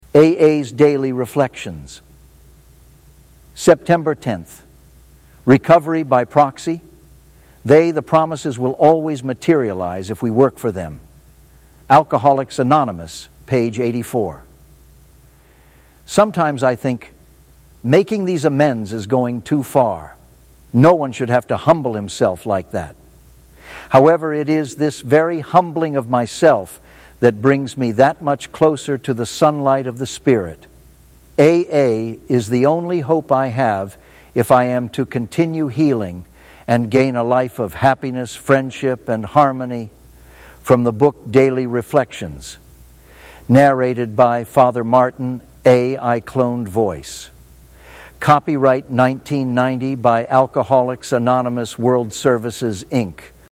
A.I. Cloned Voice